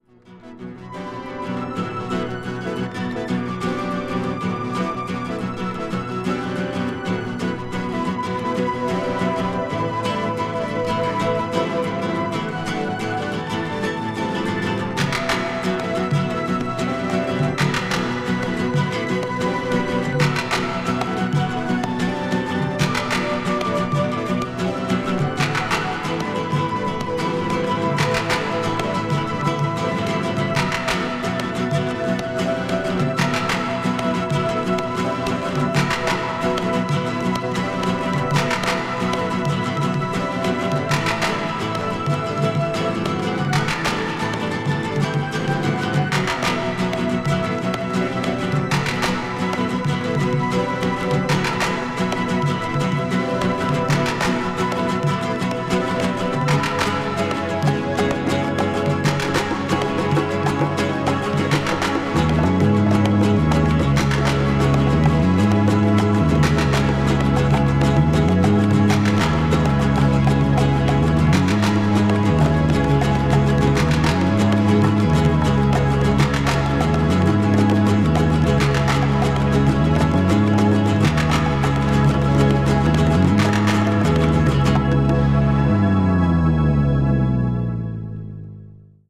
シンセサイザーによるインスト・トラックも多数収録されているところもサントラならでは。